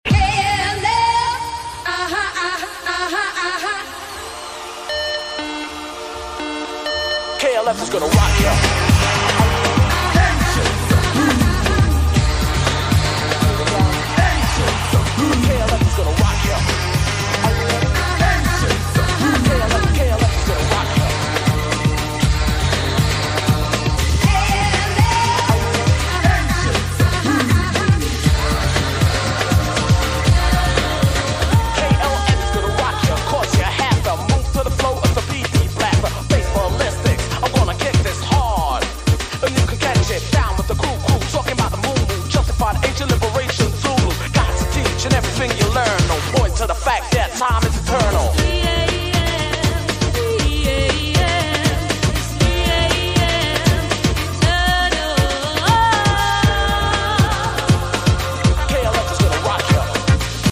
EURO HOUSE, POP RAP, HIP HOP, HOUSE